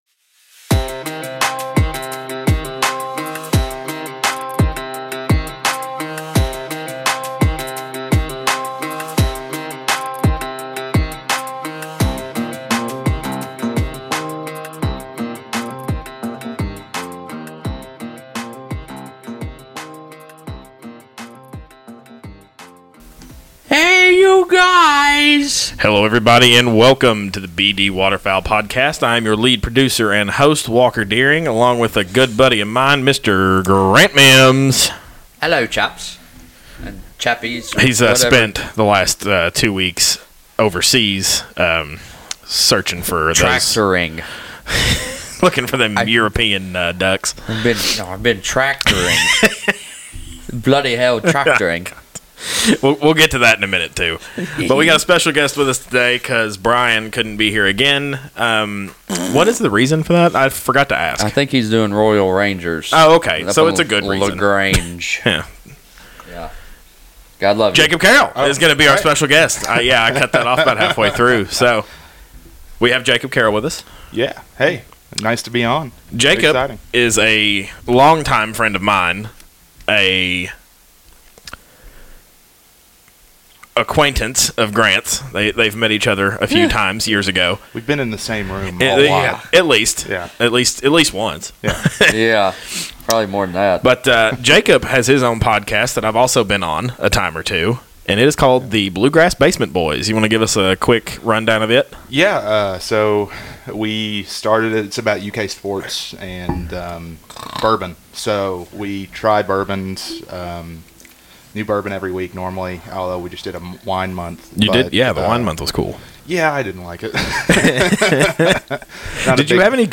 We are four guys, just winging it.